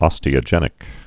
(ŏstē-ə-jĕnĭk)